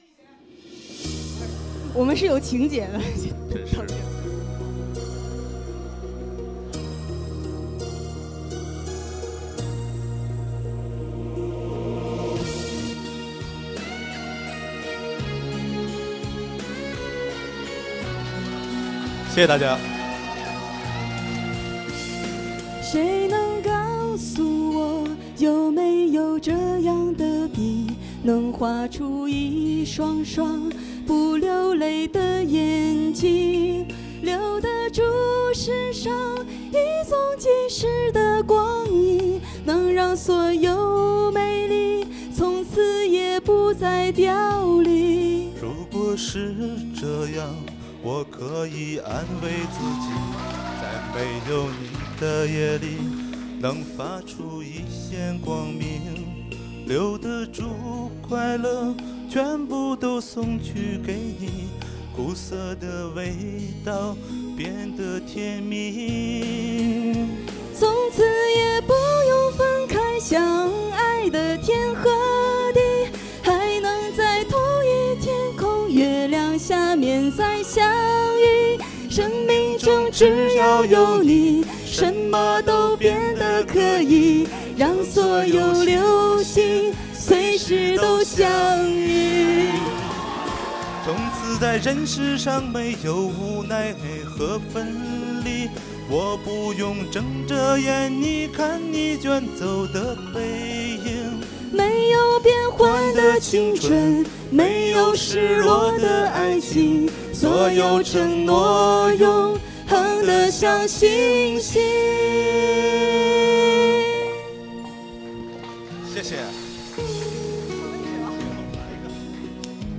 La finale du 1er concours de chansons chinoises
le lundi 9 mai à 18h30 à l'Espace Culture de l'Université Lille 1